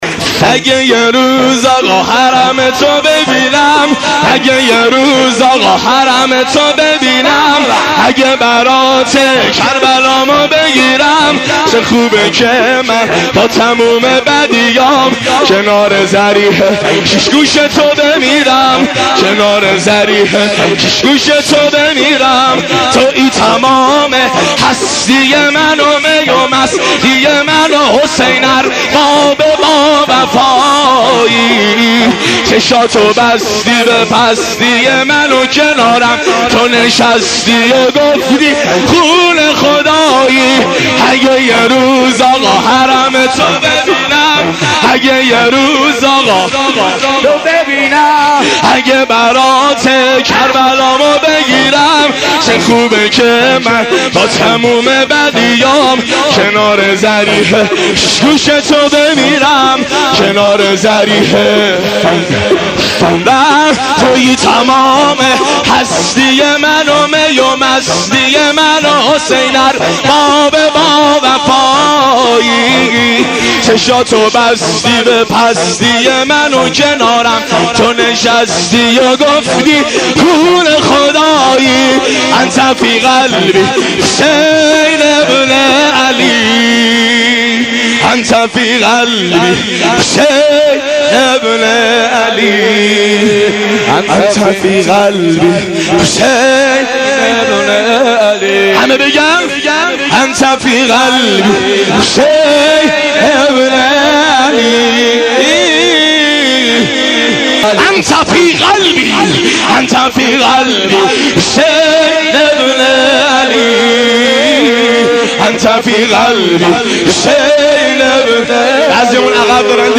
شعر شور سینه زنی شهادت امام حسین( اگه یه روز آقا حرمتو ببینم)